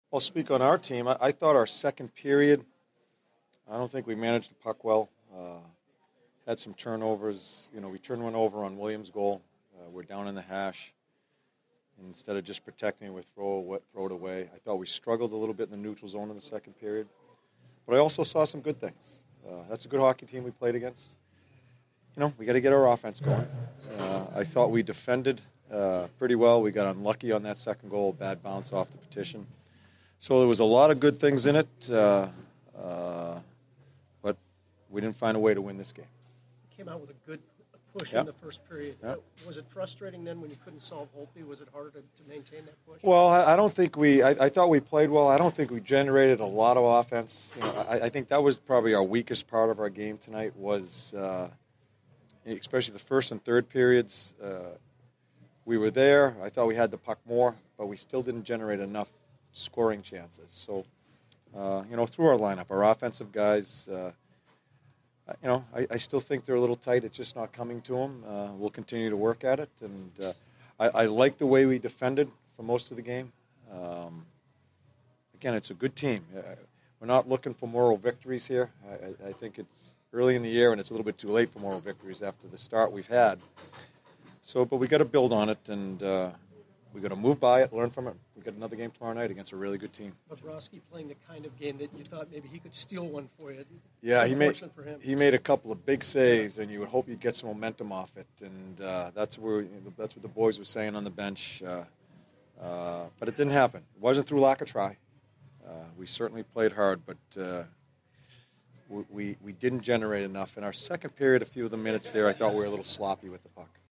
John Tortorella Post-Game 10/30/15